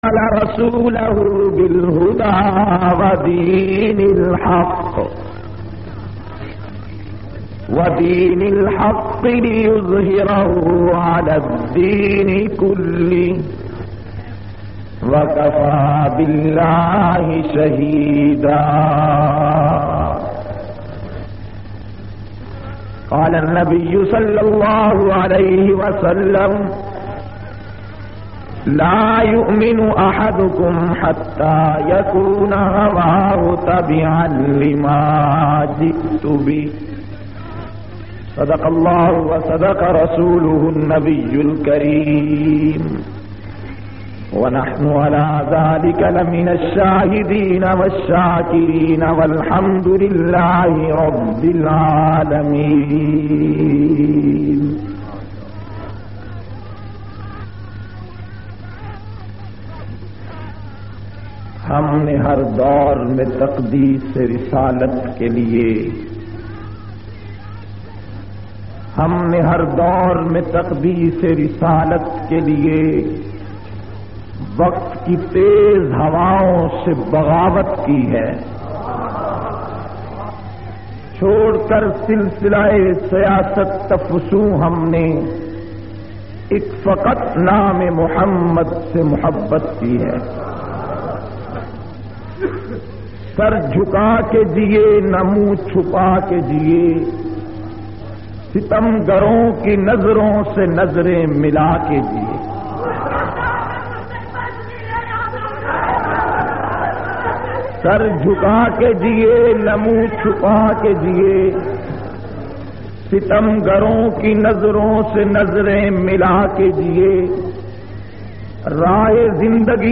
787- Salana Jalsa,Darul Uloom Hanfia-Kehroor Pakka(Tarikhi Khitab).mp3